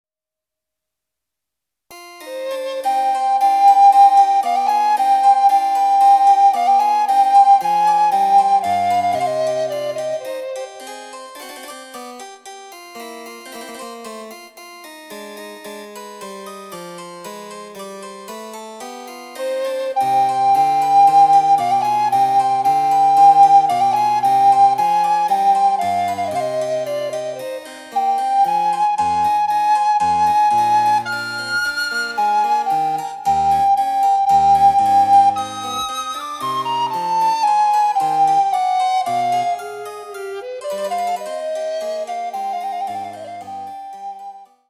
この曲の場合も、つねに着実に前に進む感じがあります。
■リコーダーによる演奏（ハ短調）
第１楽章（Ｃ−２）